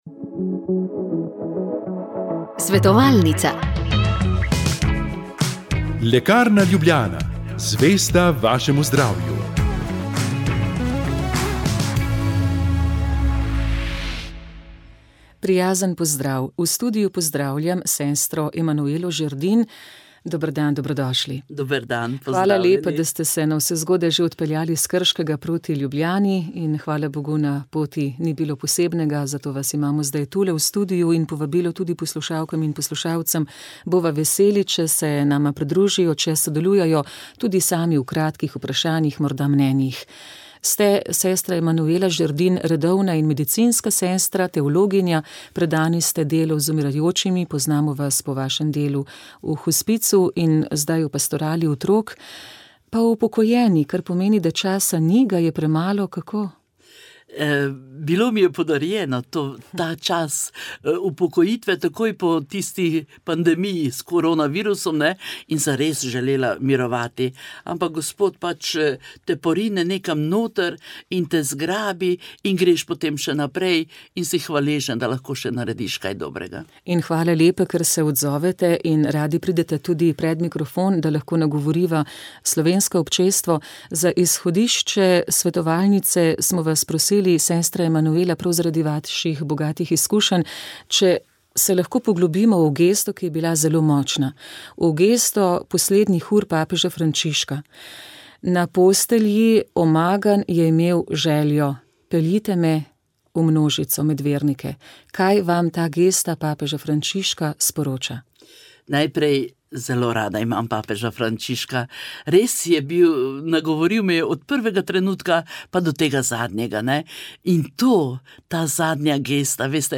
Na isti način lahko naredimo tudi piščančjo obaro. Poslušalka je dodala, da obare ne zgosti z moko ampak z drobtinami, ki jih praži na maslu.